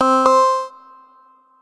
alarm.213b54a2.mp3